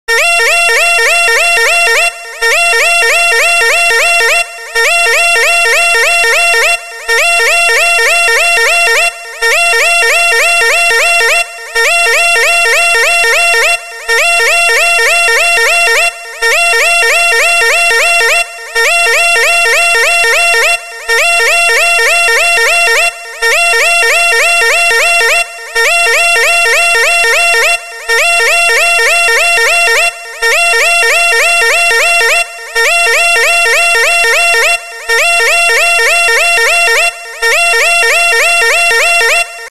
ブザー音。
ブザー音。「ウインウインウインウイン」
カテゴリ： サイレン音